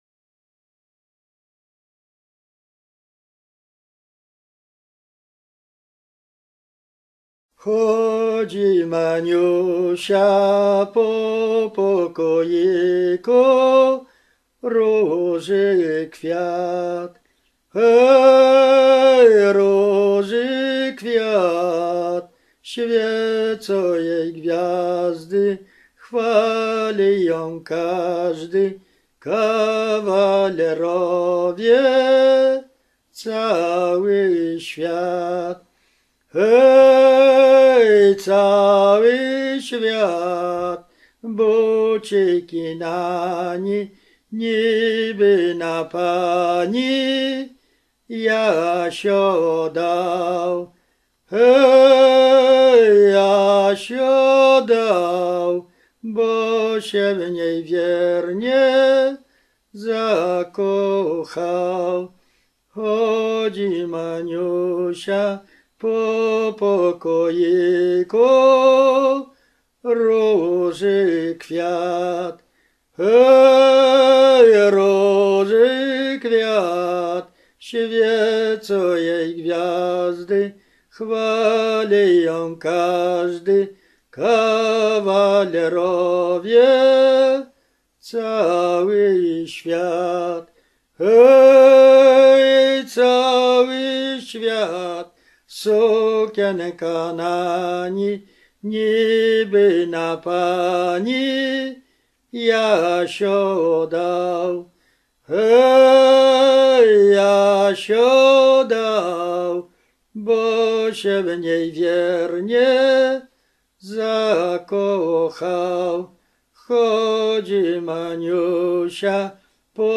Lubelszczyzna
Roztocze
Kolęda życząca